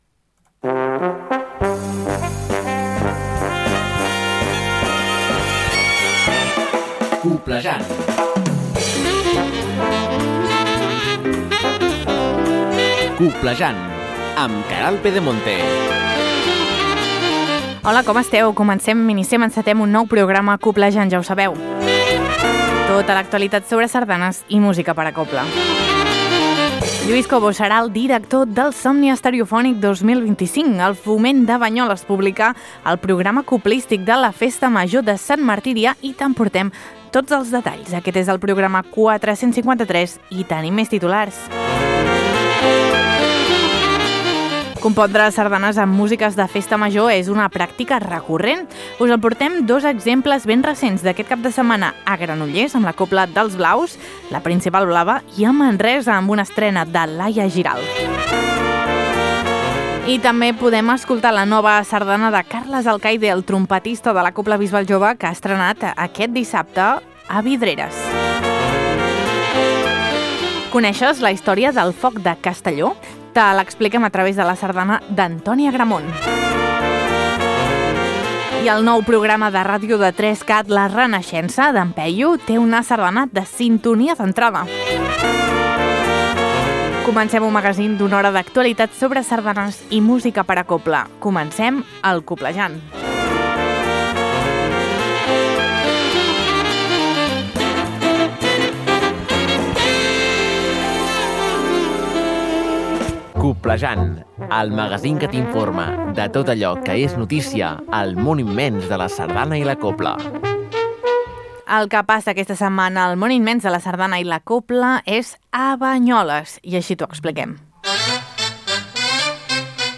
T’informa de tot allò que és notícia al món immens de la sardana i la cobla.